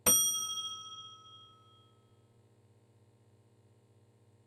BellRing.wav